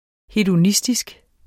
Udtale [ hedoˈnisdisg ]